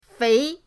fei2.mp3